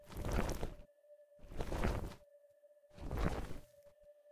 Rolling.ogg